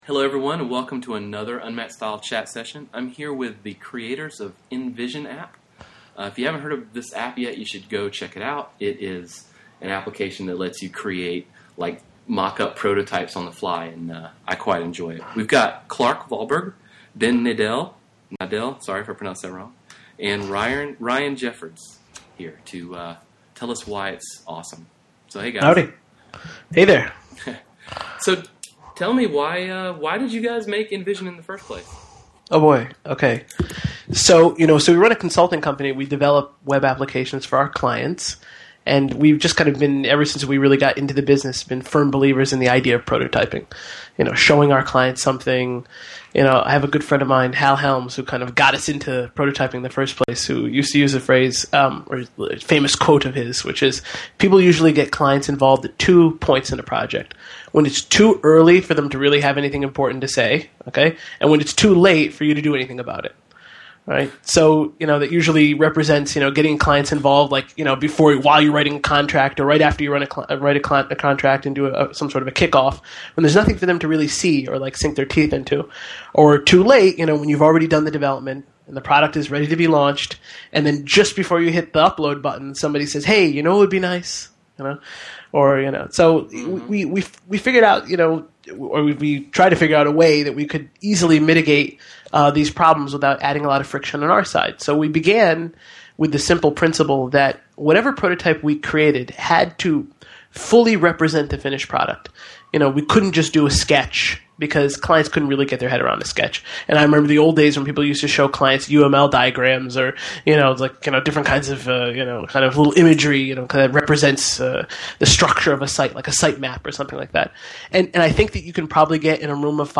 Download the InVision Interview (MP3)
Like I always do, I got the guys who made it on Skype and asked a bunch of questions about their product to see what the deal is.